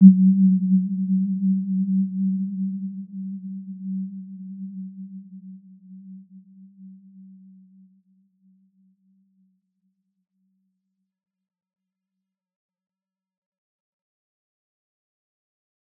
Warm-Bounce-G3-mf.wav